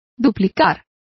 Complete with pronunciation of the translation of doubled.